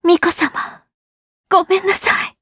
Laudia-Vox_Dead_jp.wav